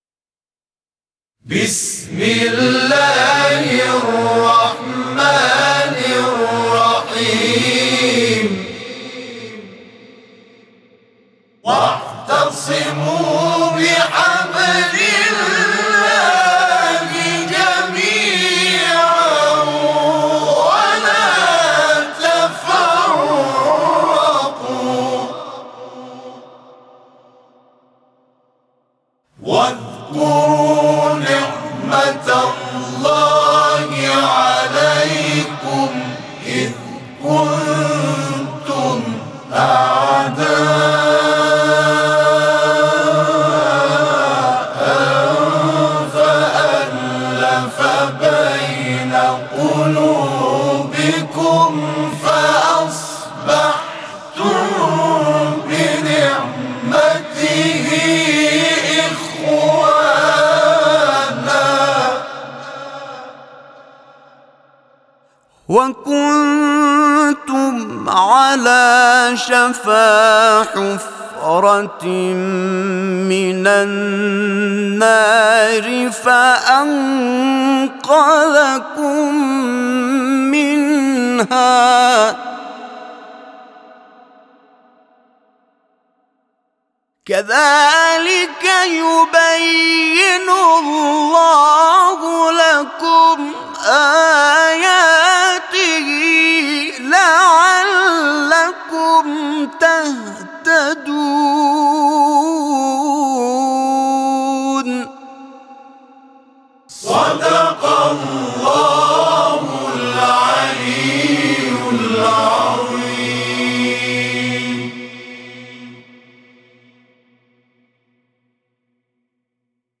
صوت همخوانی آیه 103 سوره «آل‌عمران» از سوی گروه تواشیح «محمد رسول‌الله(ص)»